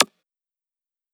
Perc (Try Me).wav